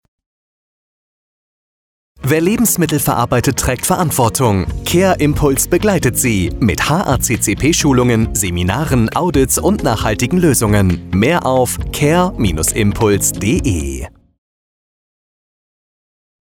Radiobeitrag
Funkspot_Care-Impuls-GmbH-12-Sek_PC-VERSION.mp3